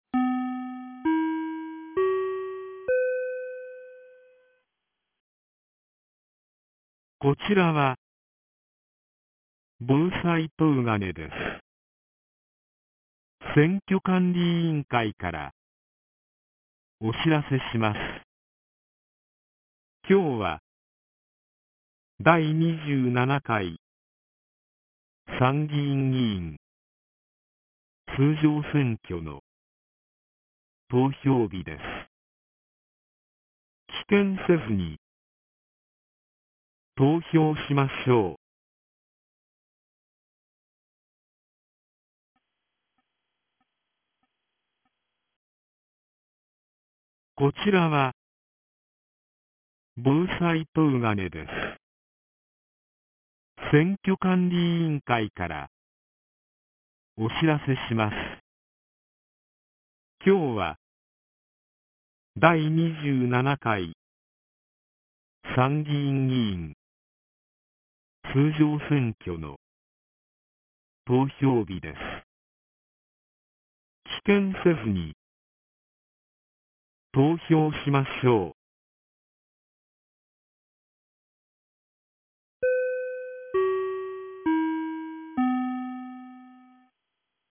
2025年07月20日 09時01分に、東金市より防災行政無線の放送を行いました。